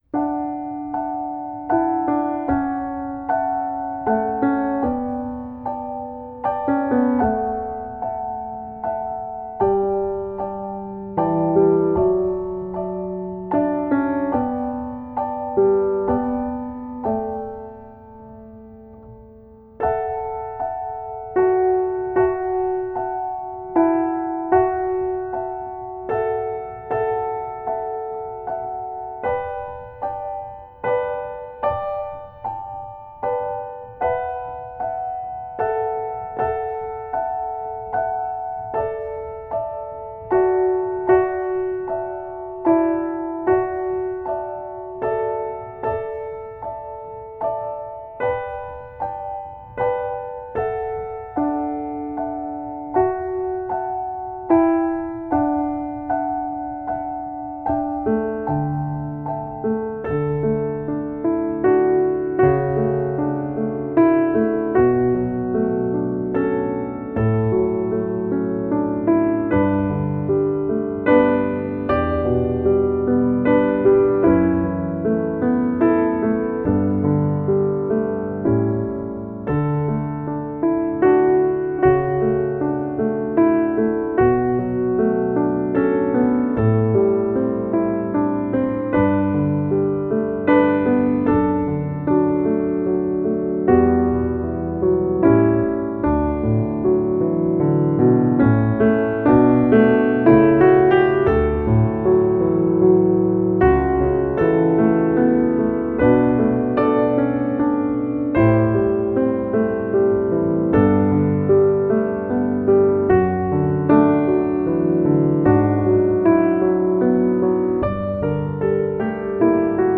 Voicing: Pno